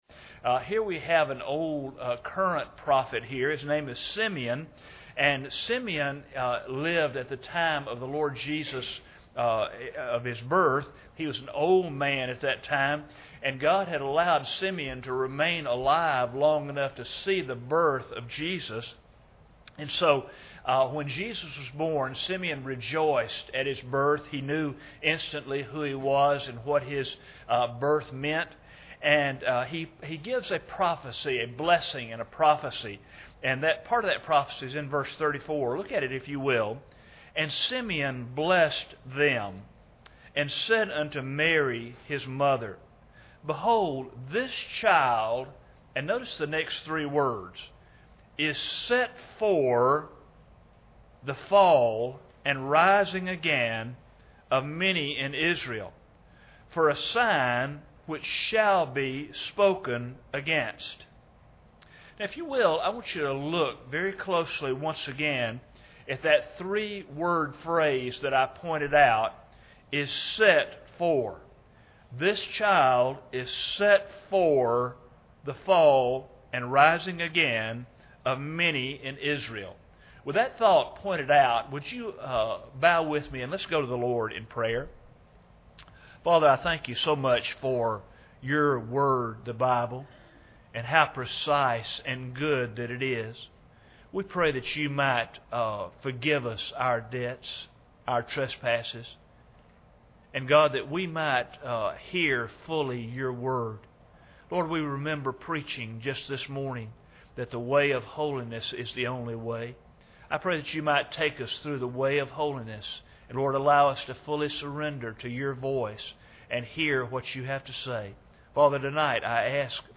Luke 2:34 Service Type: Sunday Evening Bible Text